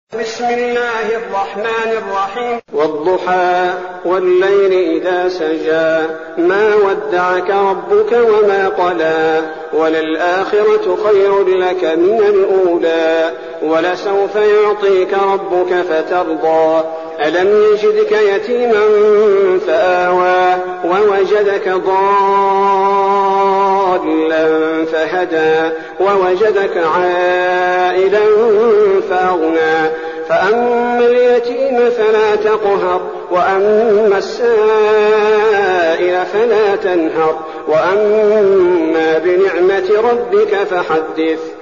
المكان: المسجد النبوي الشيخ: فضيلة الشيخ عبدالباري الثبيتي فضيلة الشيخ عبدالباري الثبيتي الضحى